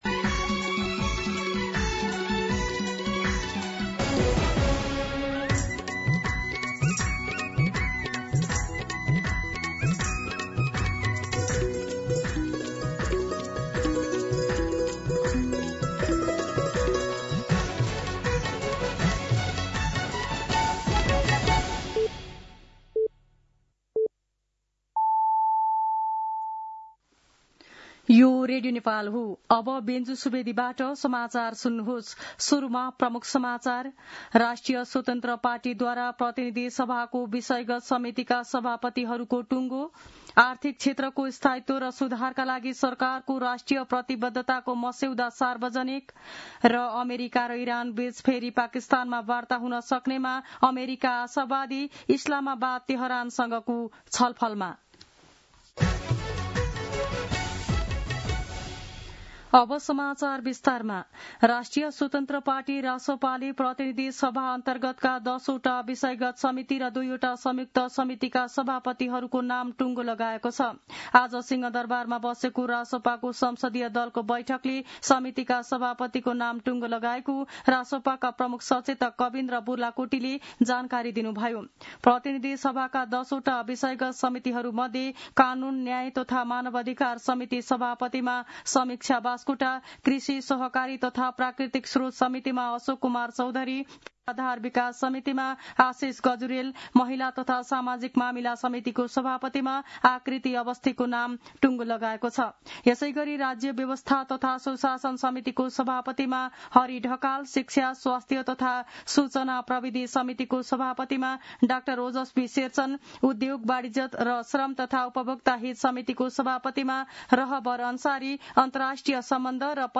दिउँसो ३ बजेको नेपाली समाचार : ३ वैशाख , २०८३
3-pm-Nepali-News.mp3